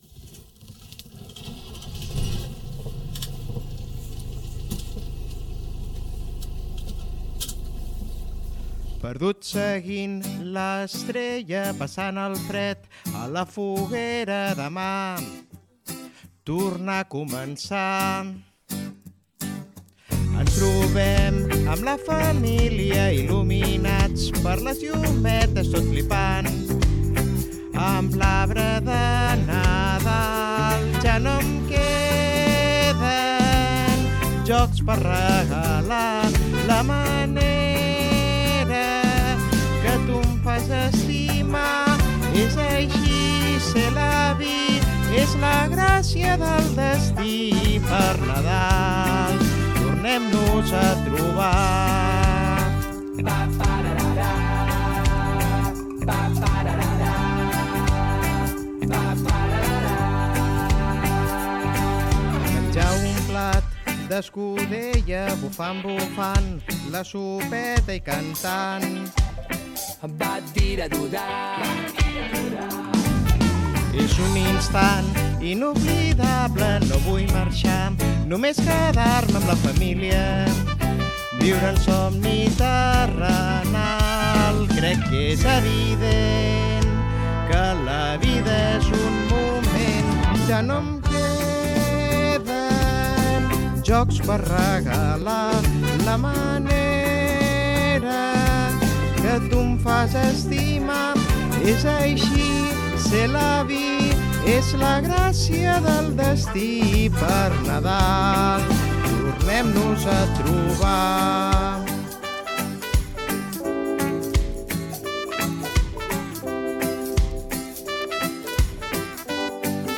Cançó 4t